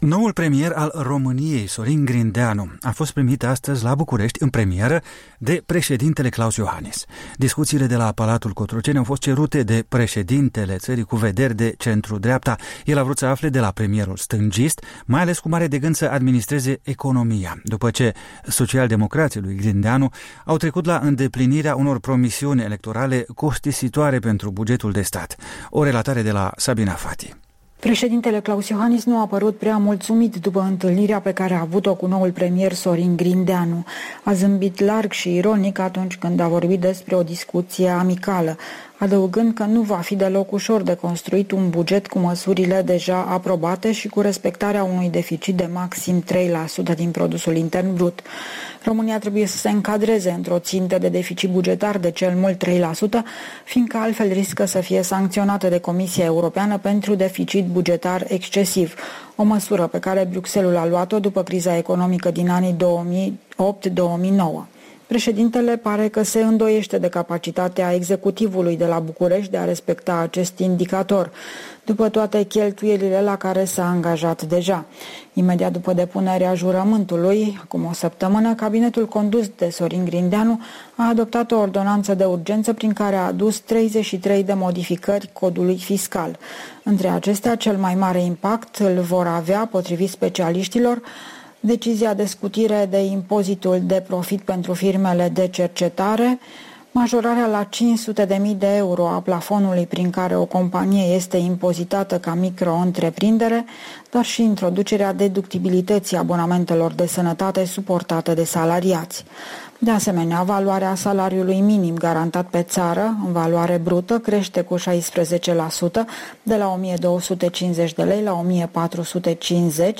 Corespondența zilei de la București